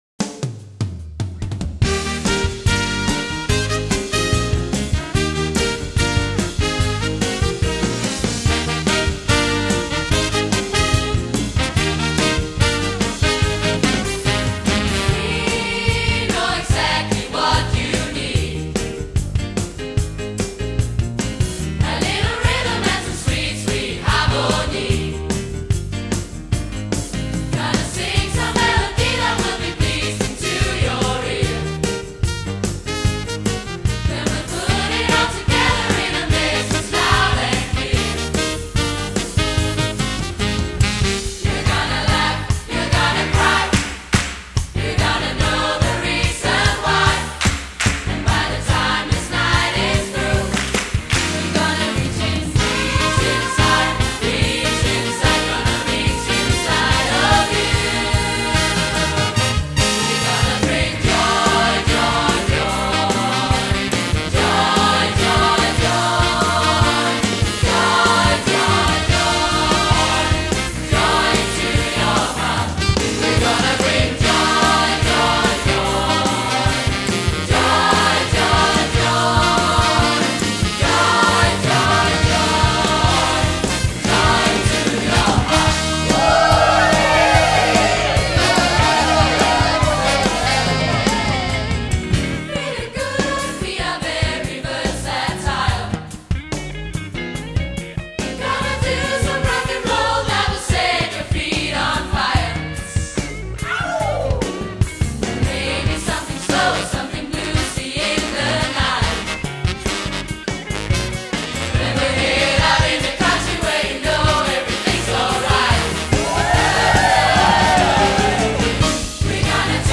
(mens du lytter til Grindsted Ungdomskor - MUK - med deres titel-sang gennem n�sten 30 �r "Joy, Joy, Joy")